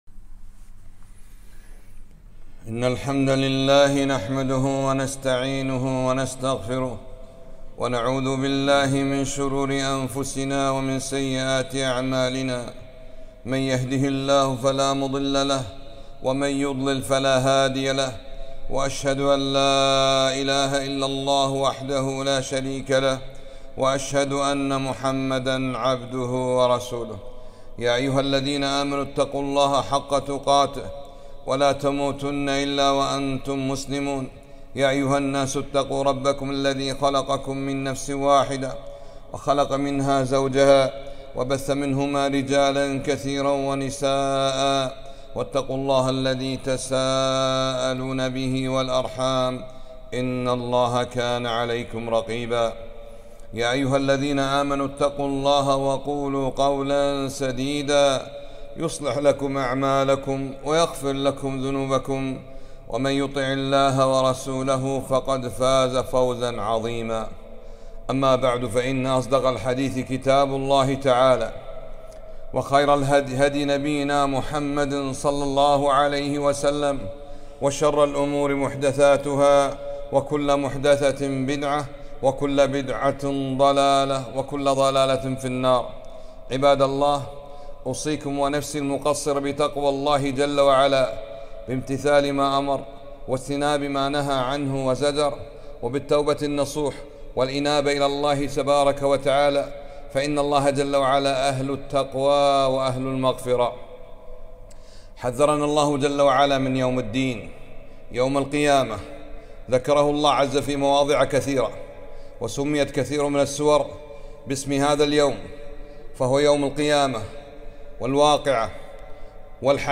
خطبة - القيامة كأنك تراها